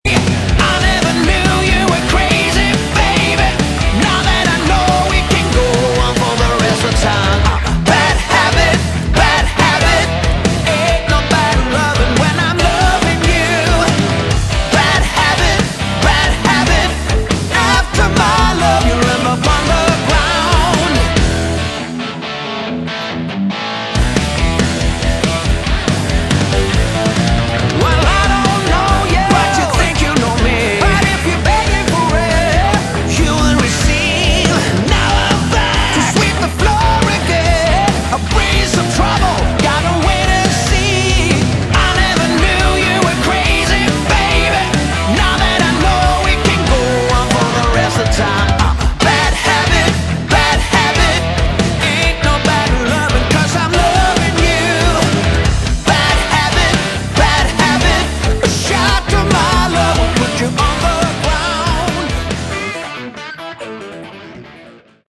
Category: Melodic Rock
lead and backing vocals, bass
guitars, synthesizers, backing vocals, percussion
drums
grand Piano, background vocals
organ